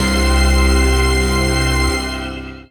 DM PAD2-56.wav